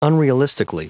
Prononciation du mot unrealistically en anglais (fichier audio)
Prononciation du mot : unrealistically